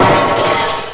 DISHCRAS.mp3